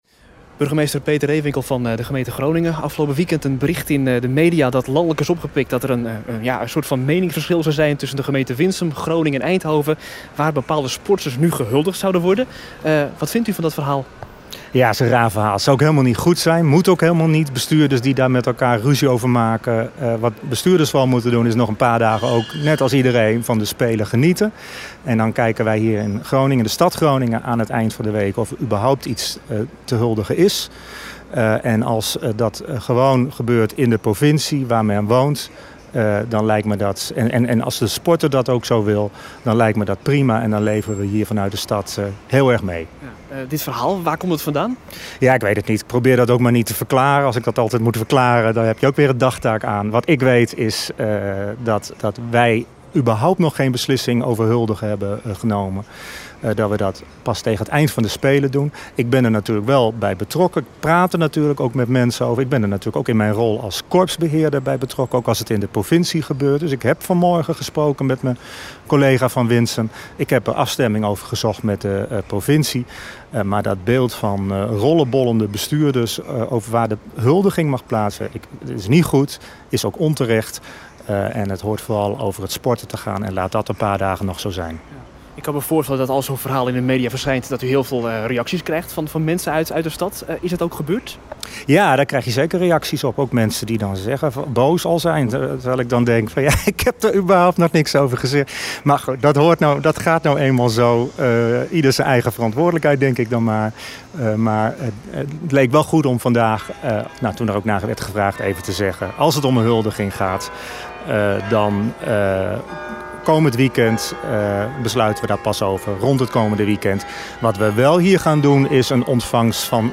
in gesprek met burgemeester Peter Rehwinkel